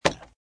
woodice.mp3